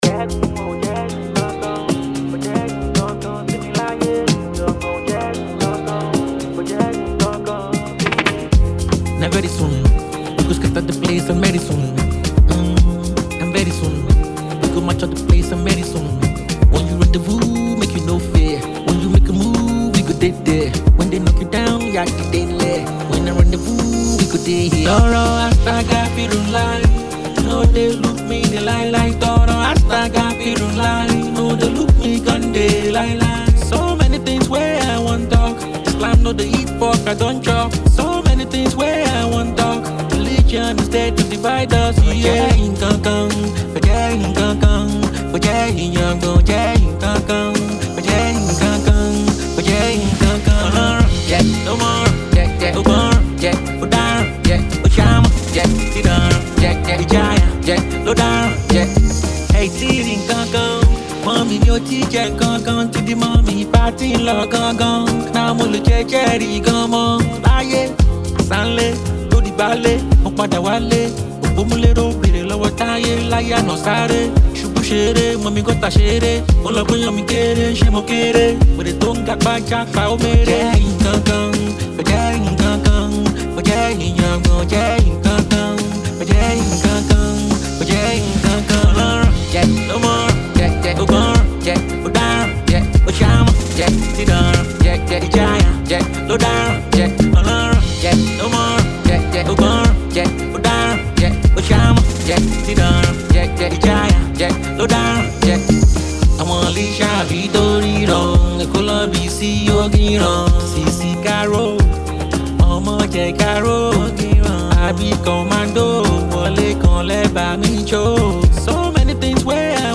Fast rising Nigerian singer